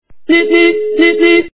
» Звуки » другие » Звуковой сигнал - СМС
При прослушивании Звуковой сигнал - СМС качество понижено и присутствуют гудки.